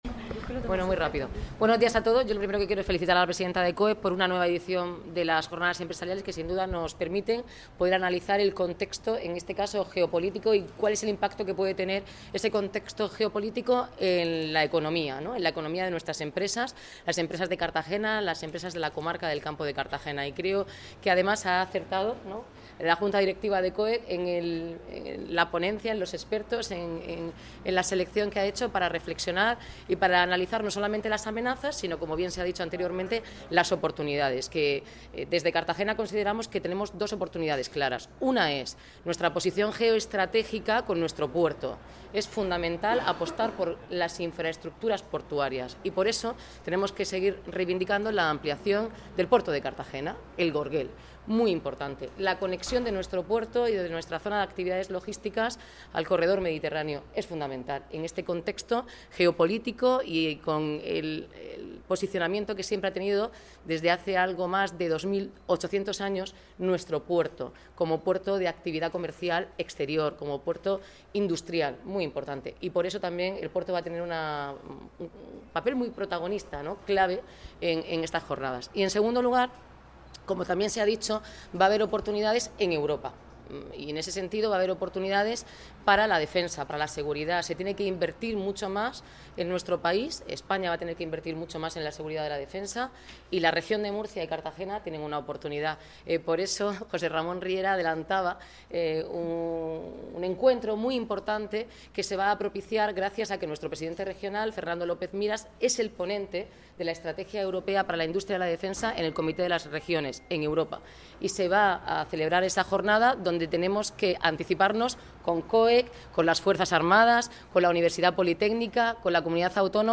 Enlace a Declaraciones de Noelia Arroyo
dos de los ponentes de las jornadas de COEC